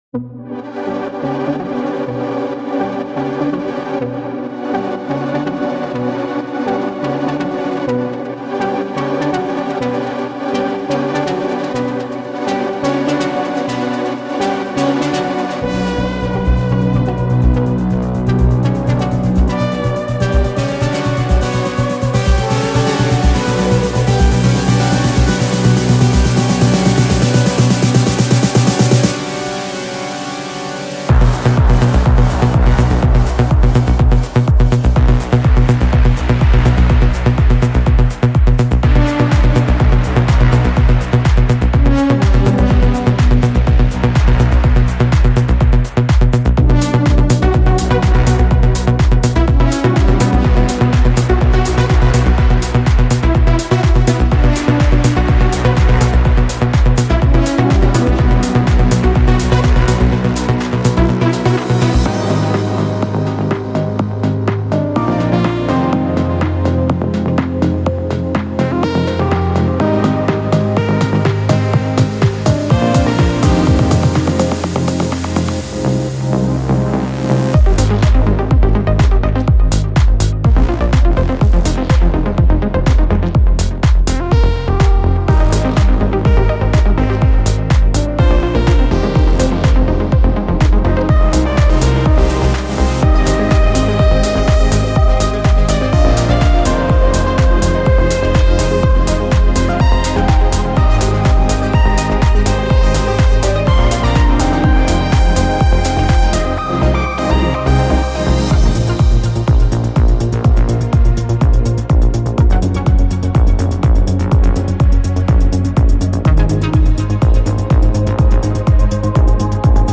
【Melodic Techno风格血清预设套装】Standalone-Music TALES Melodic Techno Serum presets
我们花了无数小时来尝试提供真正的模拟音调，甚至使用非常规技术重新创建了Filter FM和老式合唱效果，结果简直令人赞叹。